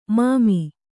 ♪ māmi